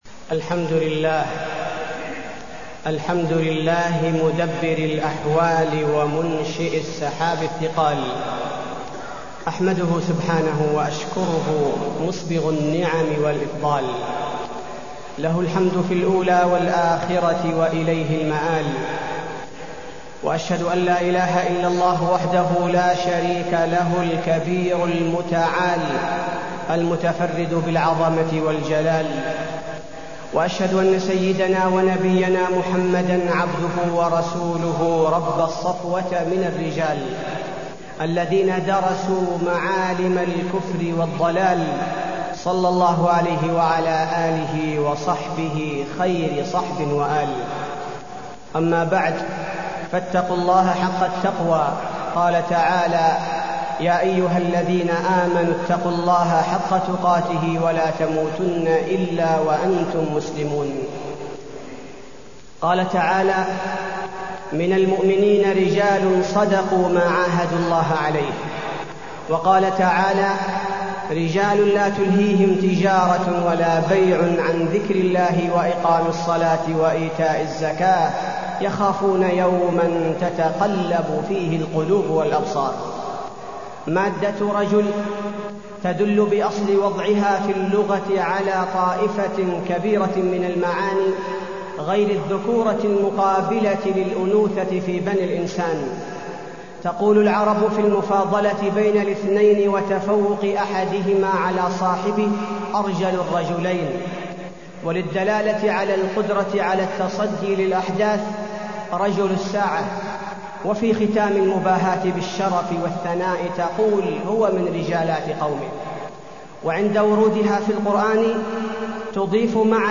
تاريخ النشر ١٧ صفر ١٤٢٢ هـ المكان: المسجد النبوي الشيخ: فضيلة الشيخ عبدالباري الثبيتي فضيلة الشيخ عبدالباري الثبيتي رجال صدقوا